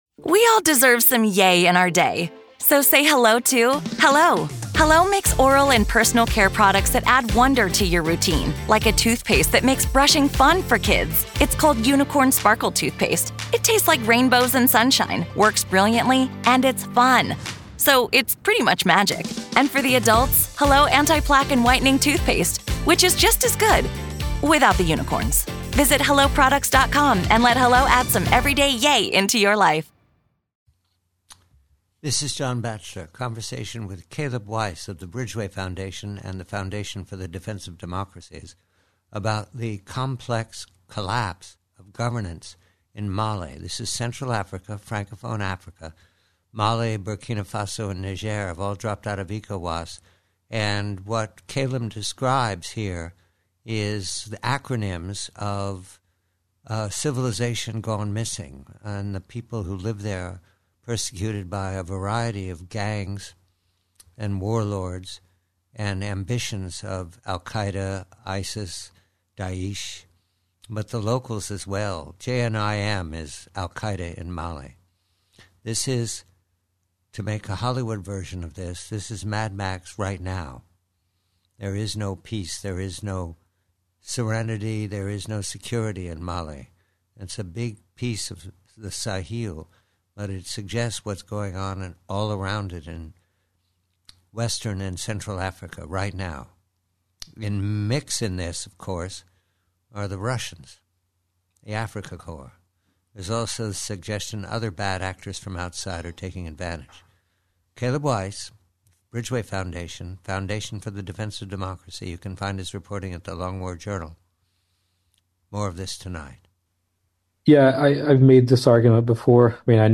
PREVIEW: MALI, AL QAEDA & MAD MAX: Conversation